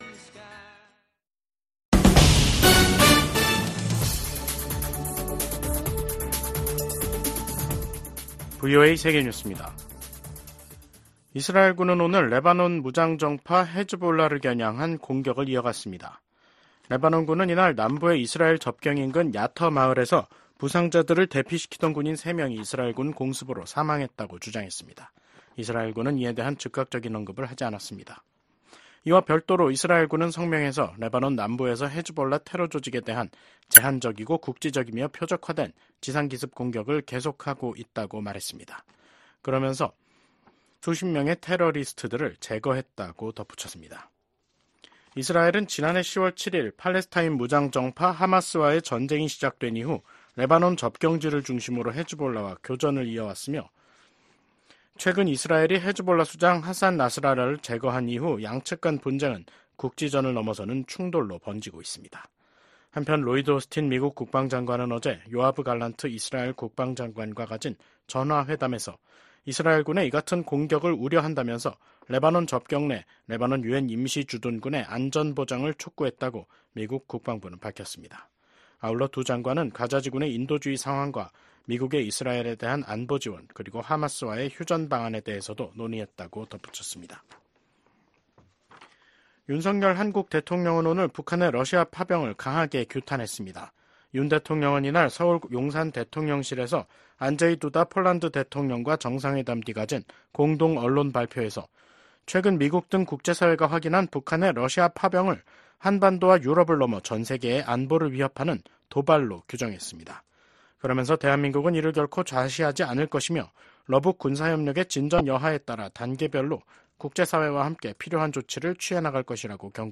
VOA 한국어 간판 뉴스 프로그램 '뉴스 투데이', 2024년 10월 24일 3부 방송입니다. 미국 백악관이 북한군의 러시아 파병을 공식 확인했습니다. 최소 3천명이 러시아 동부 전선에 파병됐으며 훈련 뒤엔 우크라이나와의 전투에 배치될 가능성이 있다고 밝혔습니다.